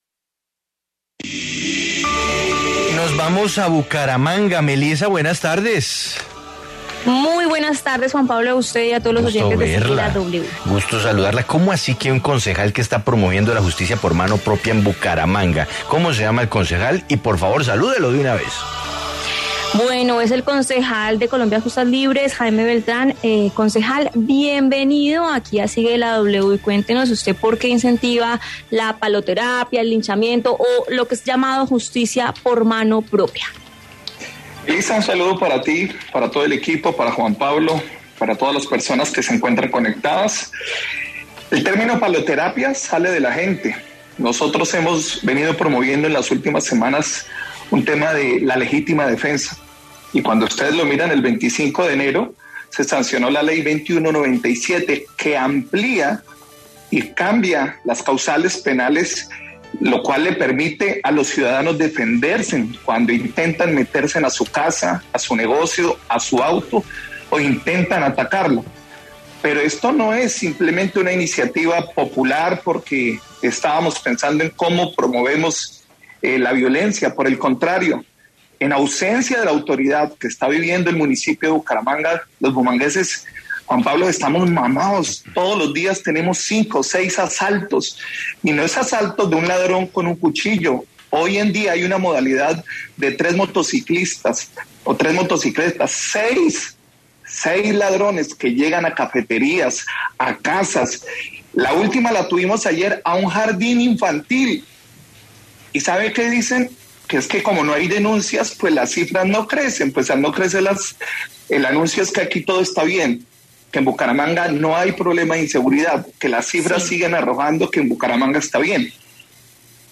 Jaime Andrés Beltrán, concejal de Bucaramanga, habló en Sigue La W sobre su propuesta de justicia a mano propia.